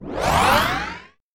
Boost or Jump 4 (1).mp3